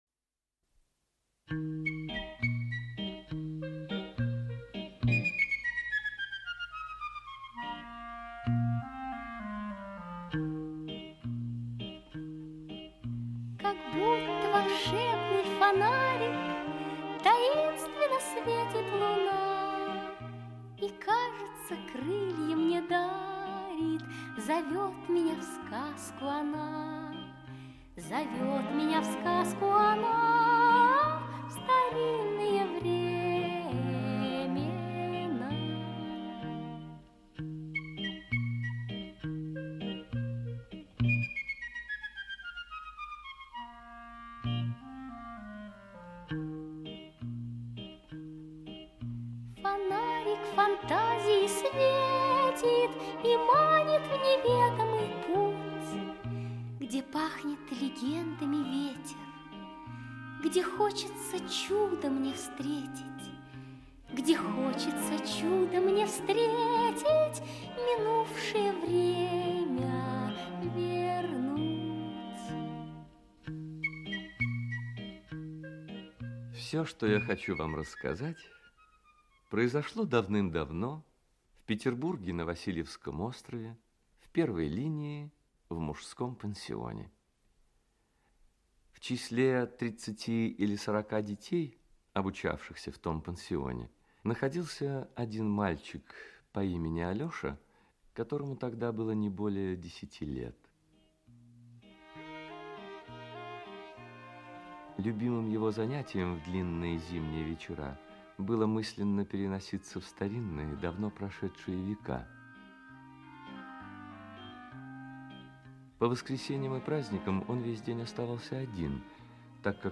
Аудиокнига Черная курица | Библиотека аудиокниг
Aудиокнига Черная курица Автор Антоний Погорельский Читает аудиокнигу Лев Дуров.